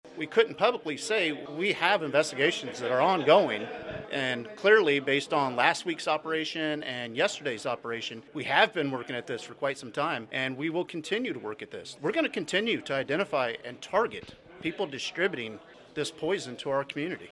On Wednesday, leadership from RCPD, the Kansas Highway Patrol and Drug Enforcement Administration gathered at the Riley County Attorney’s Office building to announce four more arrests as part of their ongoing investigation.